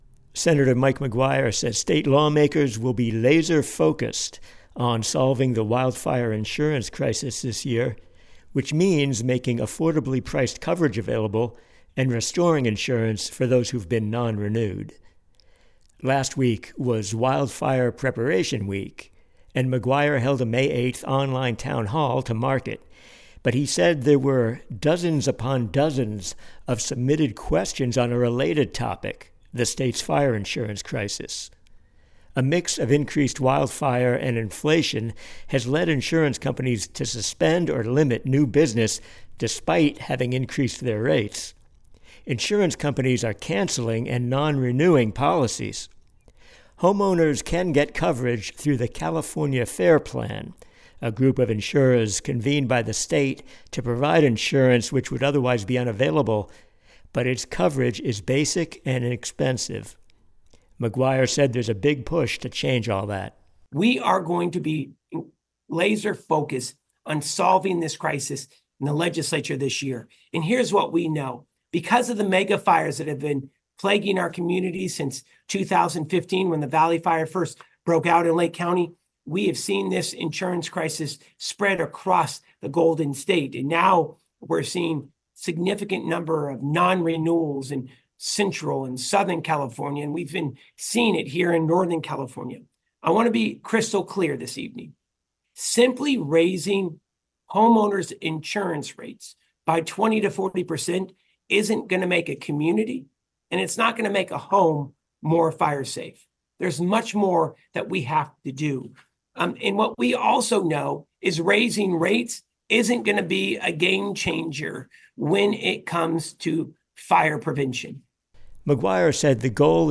The challenges of getting and keeping wildfire insurance are at a crisis level but during a recent town hall event, Senator Mike McGuire promised a legislative response.